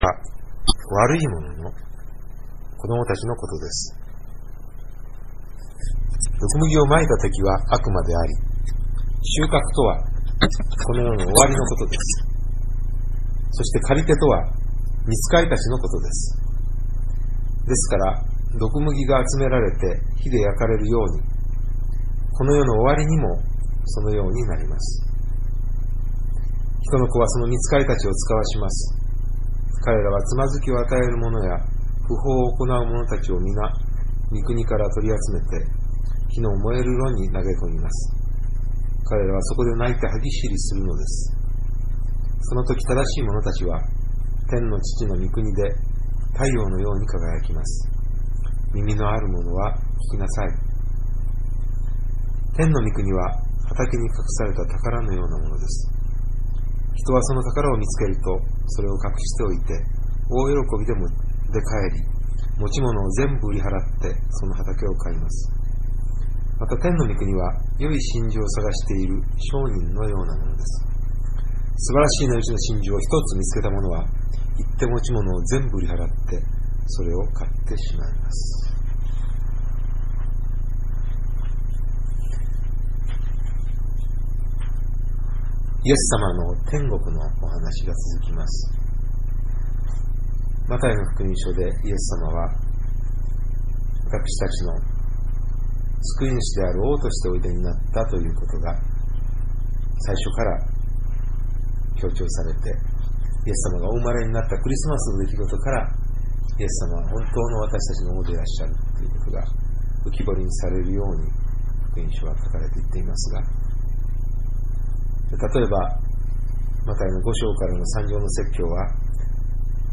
（岡本契約RPC祈祷会 ２００８年4月２３日）